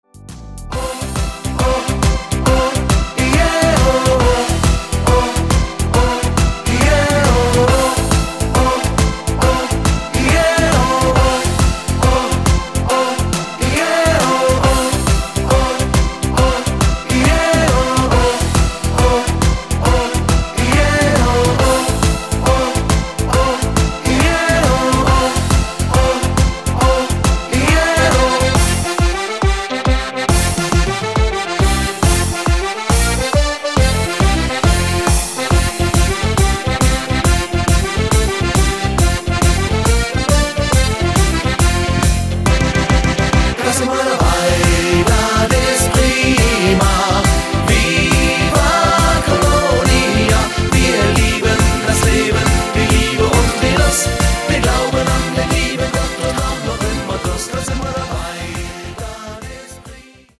Rhythmus  Party Shuffle
Art  Deutsch, Kölsche Hits, ML Remix, Neuerscheinungen